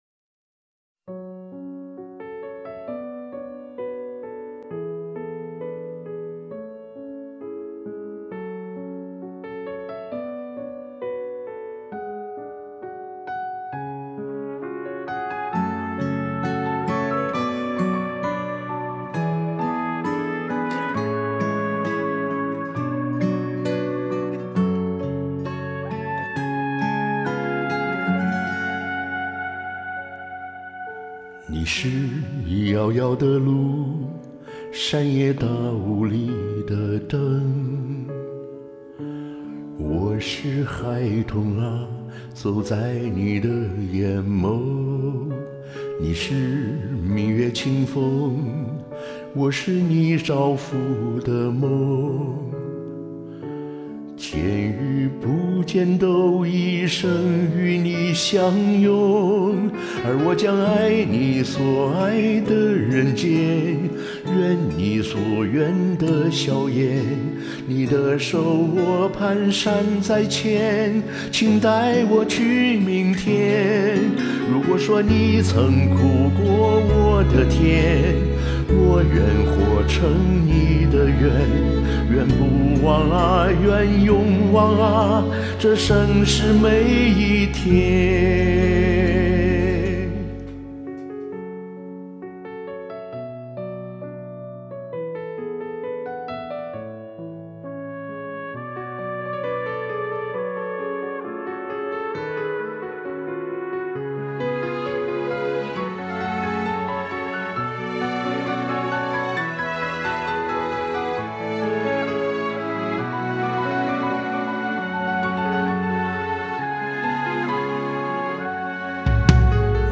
染上新冠两个多礼拜了，咽喉一直不清爽，说话还有很重鼻音，昨天感觉好些，赶紧录两首：）